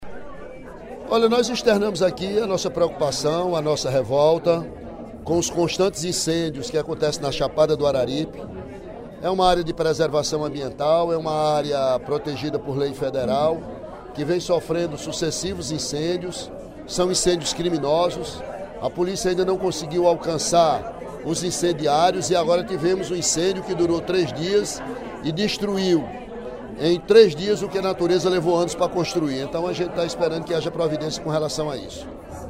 Os constantes incêndios ocorridos na Chapada do Araripe, região do Cariri, foram citados, nesta terça-feira (10/11), pelo deputado Ely Aguiar (PSDC), durante o primeiro expediente da sessão plenária da Assembleia Legislativa.